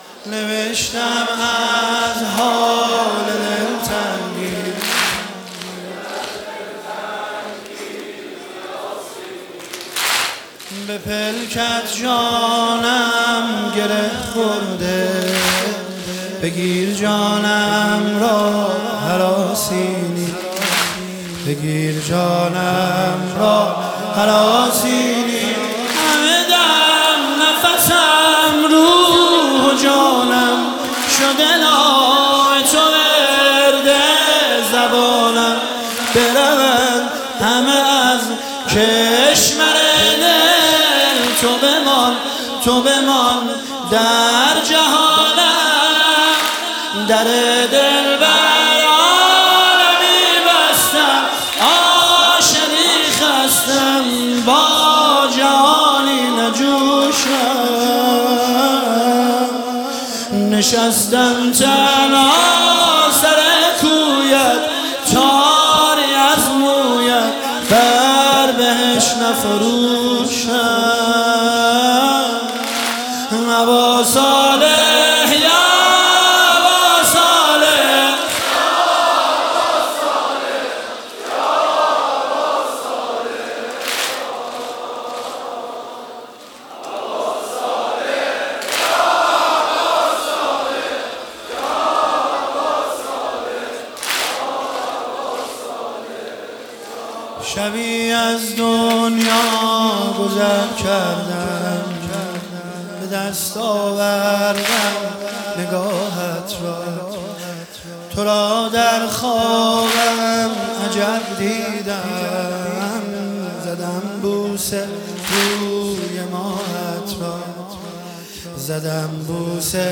شب 21 ماه رمضان 1446